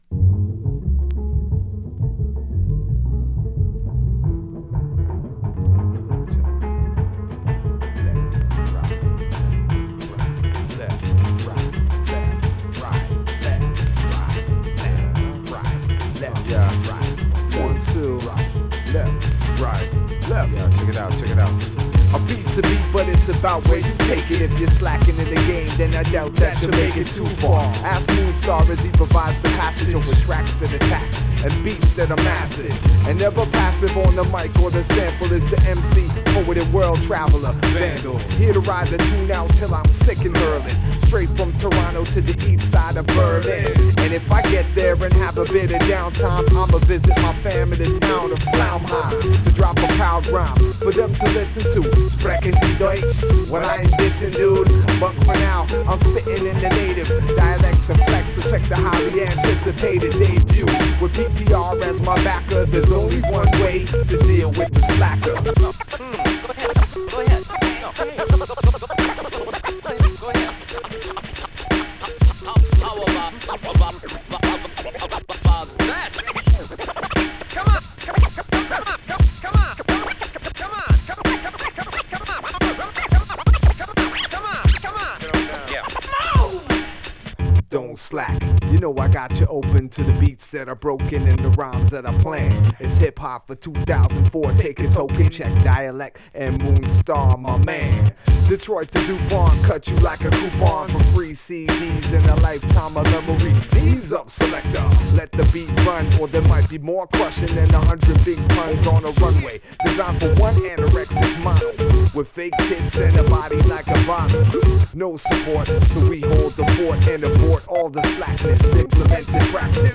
タイトル通りデトロイトテクノから受けたテックハウスとなっています。